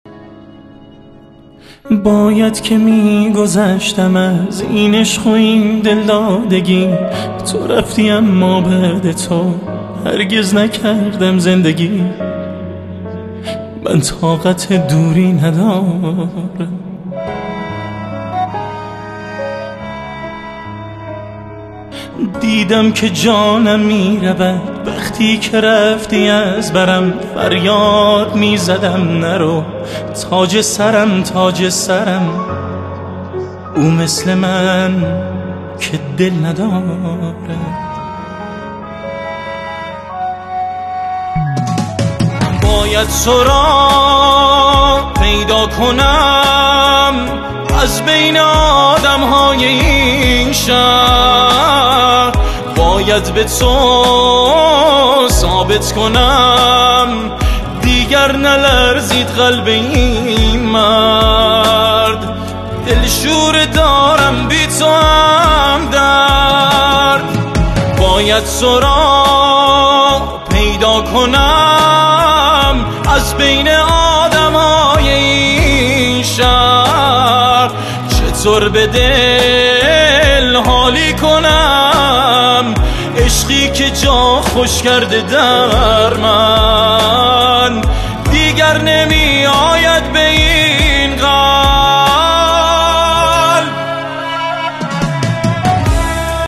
آهنگ غمگین جدید